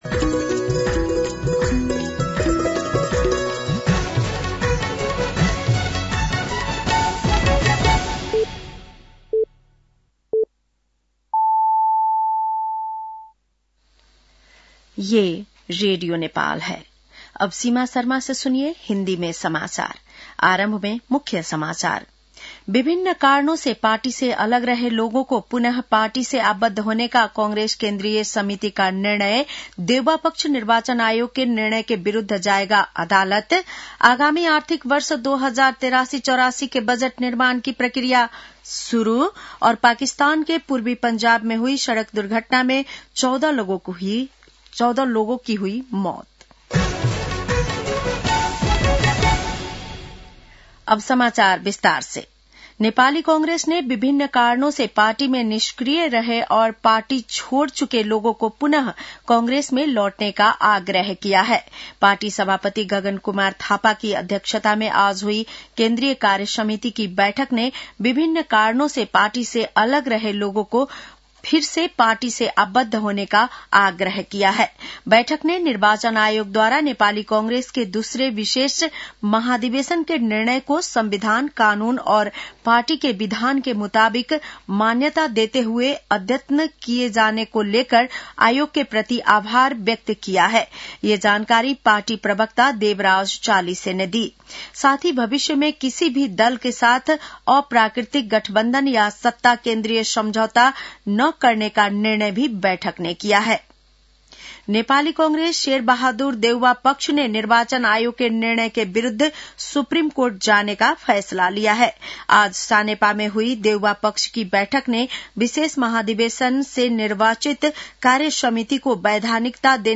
बेलुकी १० बजेको हिन्दी समाचार : ३ माघ , २०८२